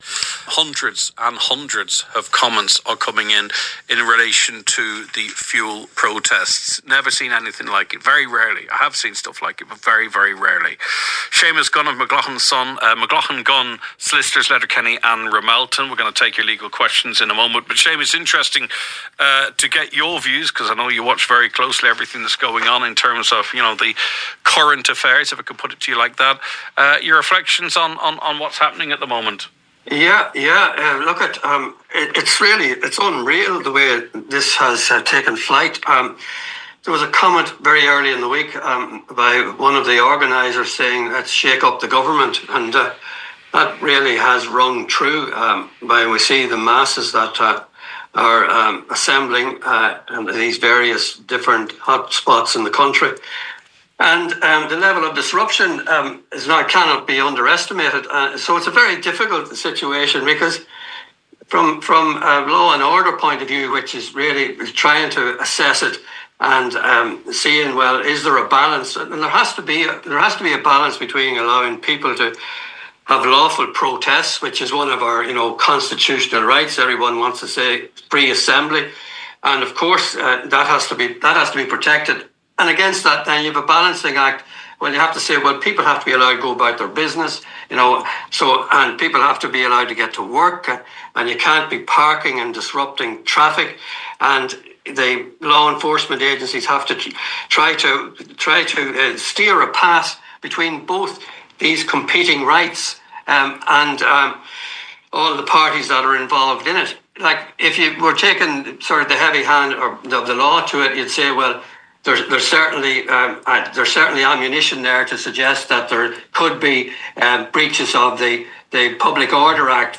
As always, the full interview and Q&A that followed can be listened to above.